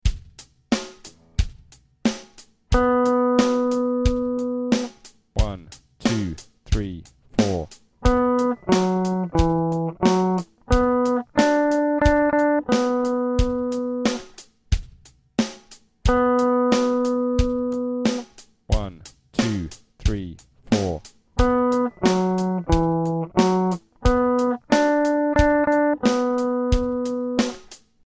These are a 2 bar melody using the notes of the C major pentatonic or B minor pentatonic scale. The first note will be the root note and the next a descending interval. Each test will play the root note separately before the sequence.